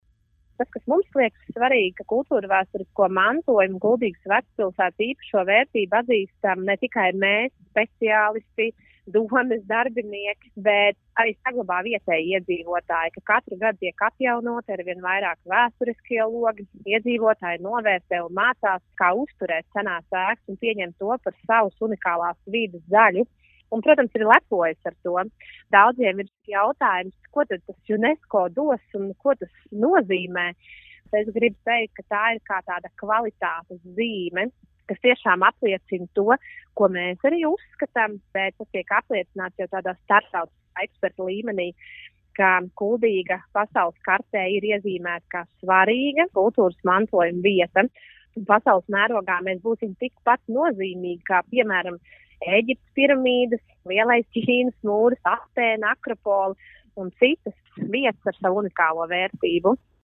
RADIO SKONTO Ziņās par gaidāmo Kuldīgas vecpilsētas uzņemšanu UNESCO Pasaules mantojuma sarakstā
Vairāk stāsta Kuldīgas novada domes priekšsēdētāja Inese Astaševska: